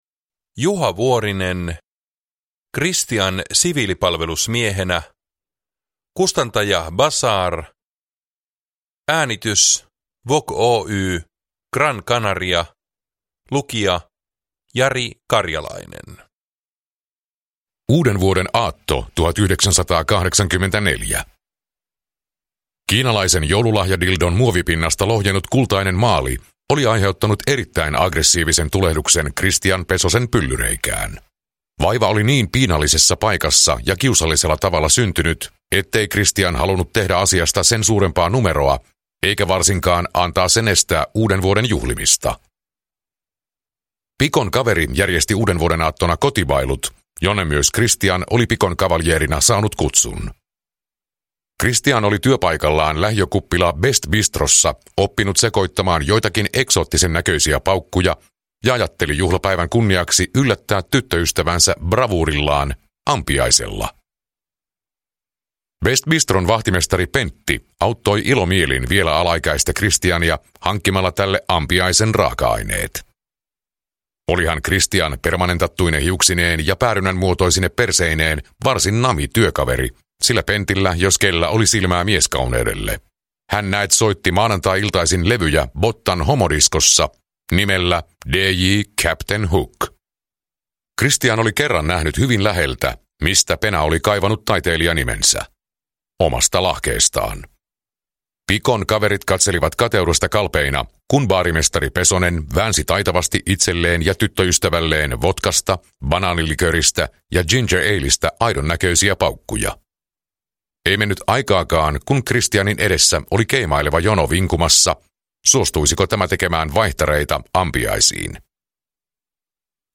Kristian siviilipalvelusmiehenä – Ljudbok